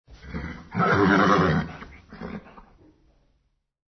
descargar sonido mp3 relincho 8